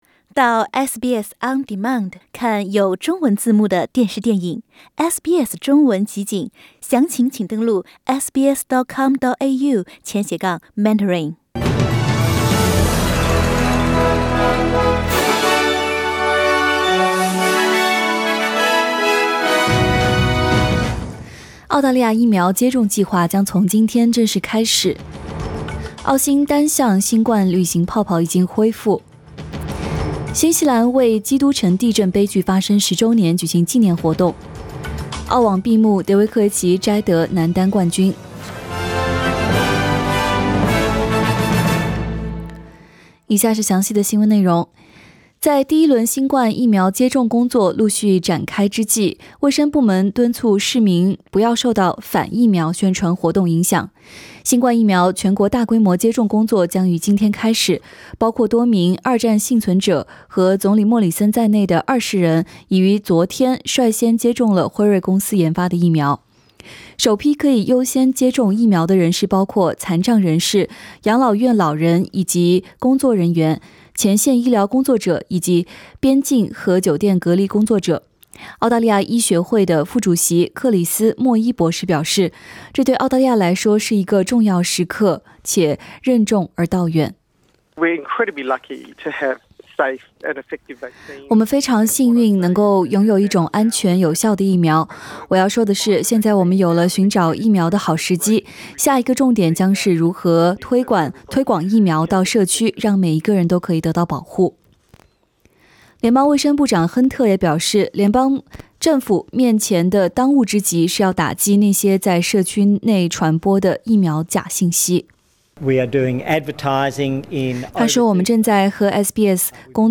SBS 早新聞 （3月22日）